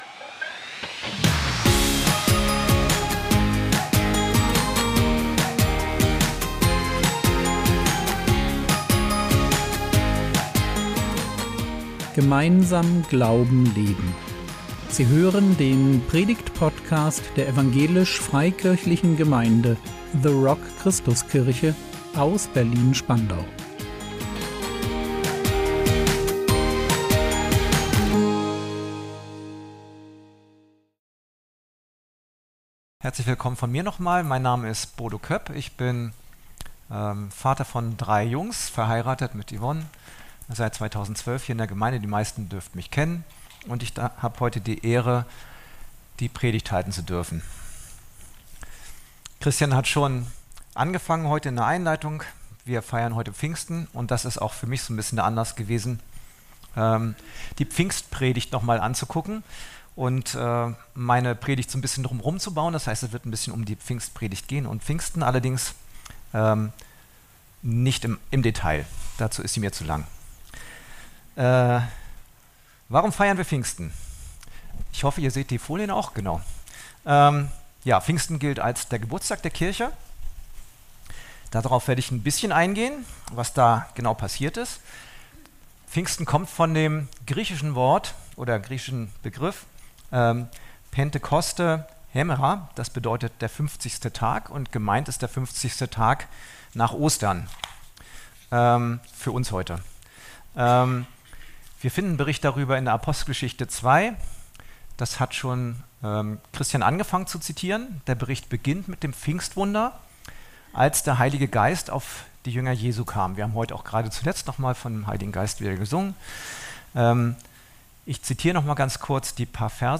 Pfingsten - War Jesus der Messias? | 08.06.2025 ~ Predigt Podcast der EFG The Rock Christuskirche Berlin Podcast